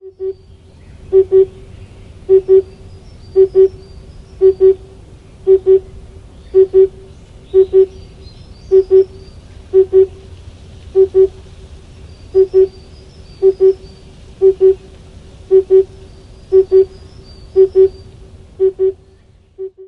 臺灣繁殖鳥類大調查 - 中杜鵑
Cuculus optatus 北方中杜鵑 別名： 筒鳥;中杜鵑 學名： Cuculus optatus,